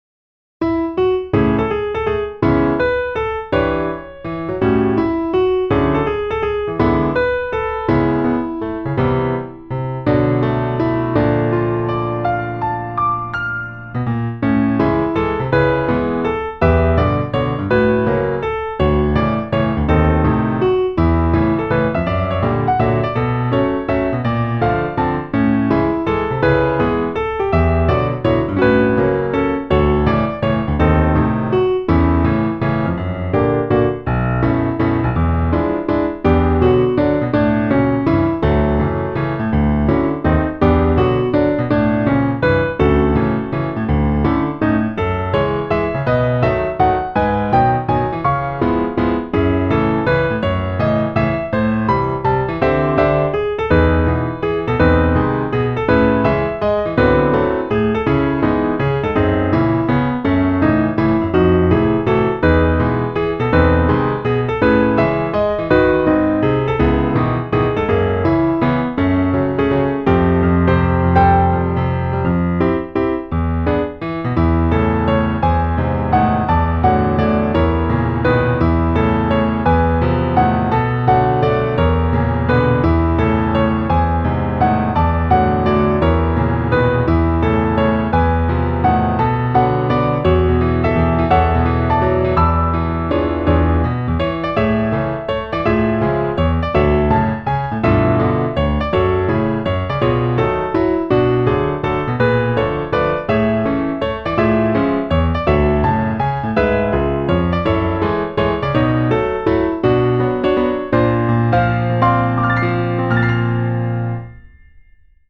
PIANO部屋 新着10曲分・一覧表示は こちら